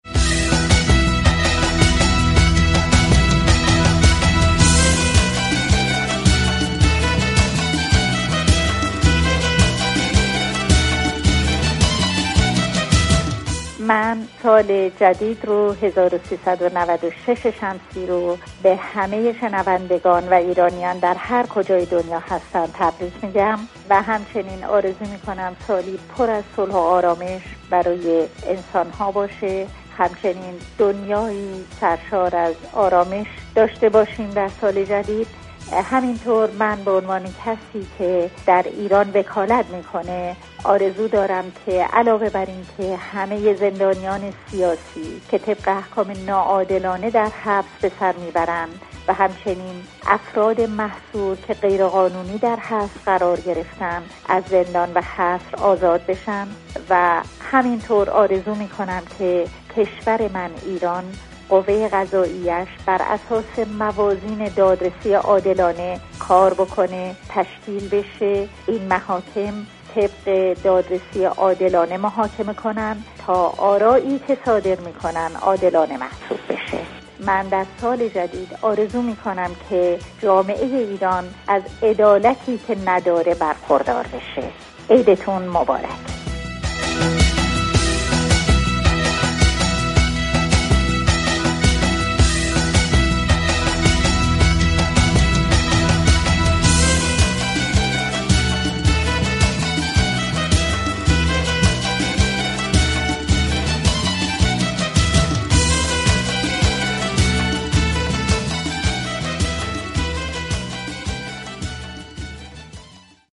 پیام نوروزی نسرین ستوده, فعال مدنی و حقوق دان